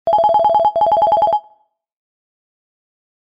Stock ringtone from the vivo X80 Pro, launched in 2022.